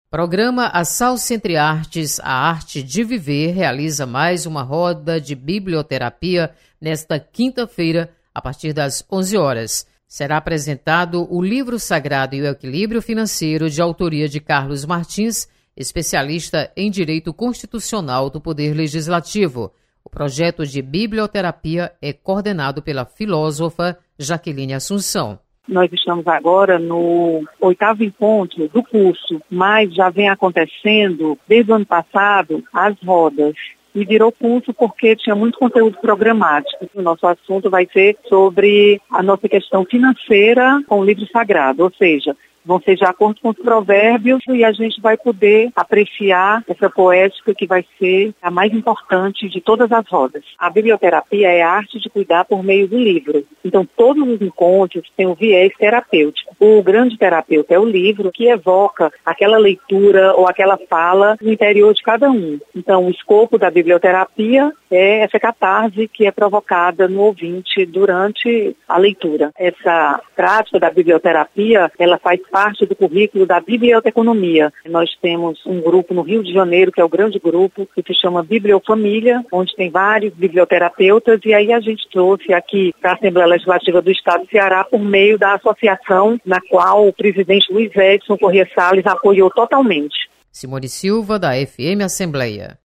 Assalce realiza mais uma roda de Biblioterapia. Repórter